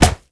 punch_head_weapon_bat_impact_03.wav